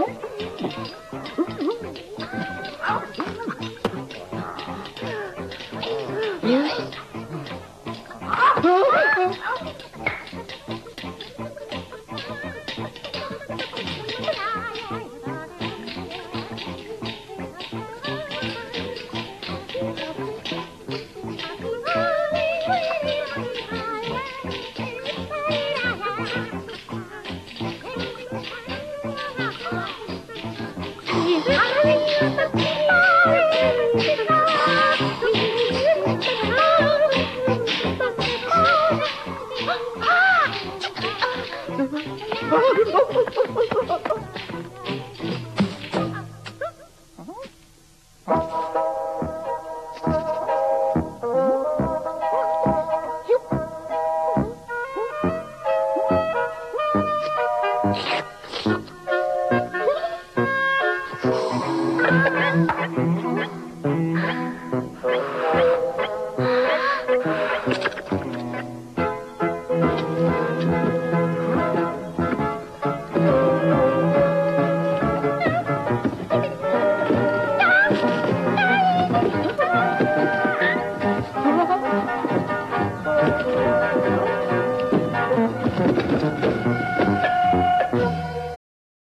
A series without narration featuring the combined technique of cartoon and puppet animation.